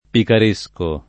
[ pikar %S ko ]